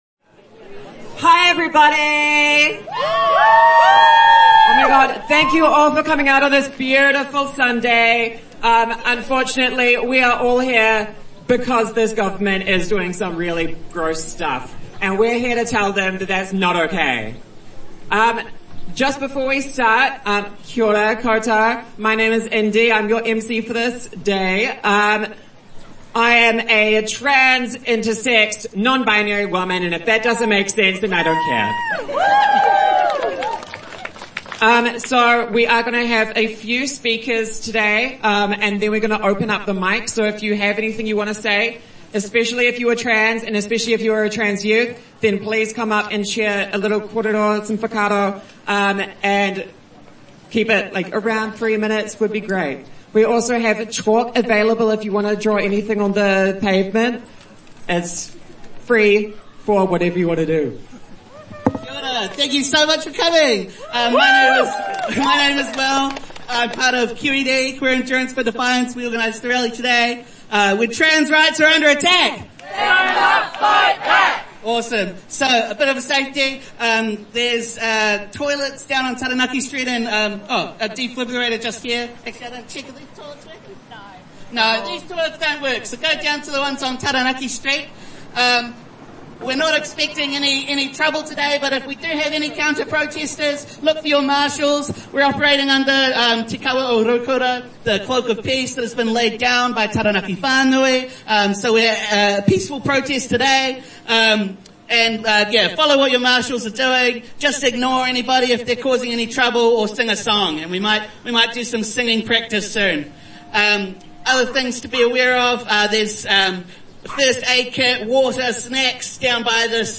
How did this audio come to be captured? Audio from Stand Up for Trans Kids event, held in Te Aro Park, Wellington on Sunday 23 November 2025.